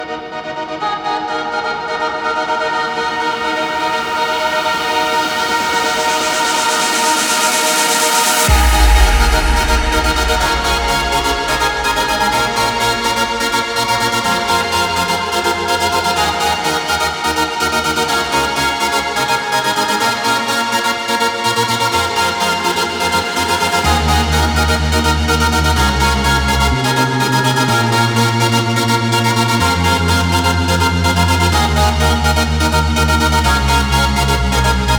Жанр: Танцевальные
# Dance